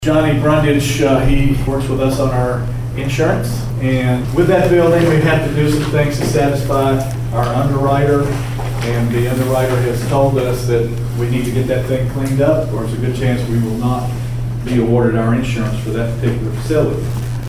Martin Mayor David Belote explained the urgency on the clean up at Martin March Finance Meeting.